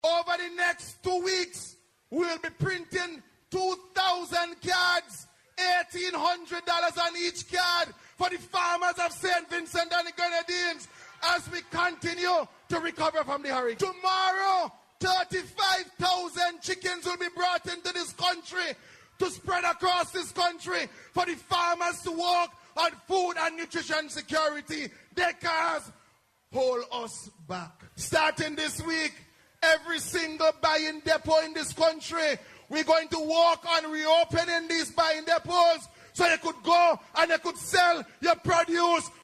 Minister of Agriculture Saboto Caesar made the announcement while speaking at the ULP’s 24th anniversary of governance celebration rally, last night.